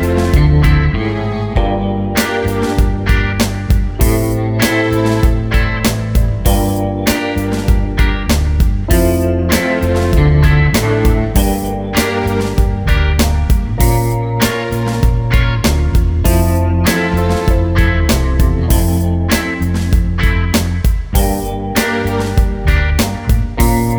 For Solo Female Pop (2000s) 4:37 Buy £1.50